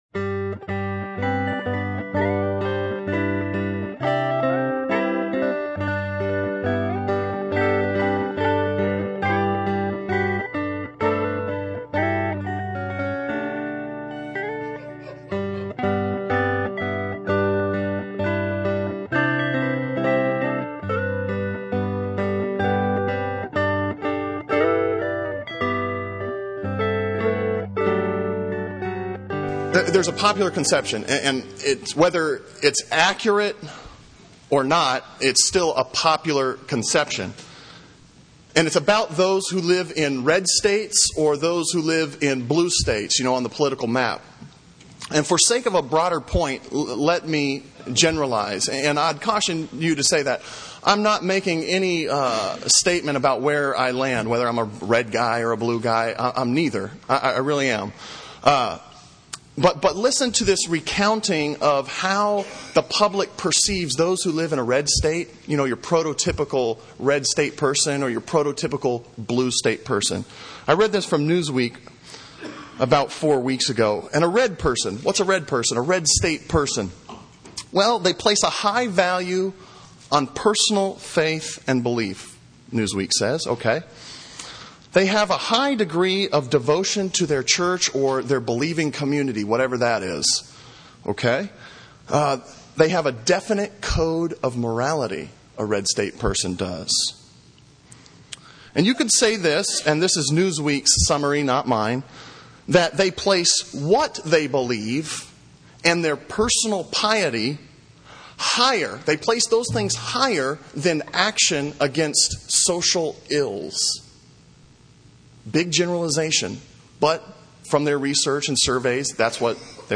Sermon on Isaiah 58:1-12 from February 22, 2007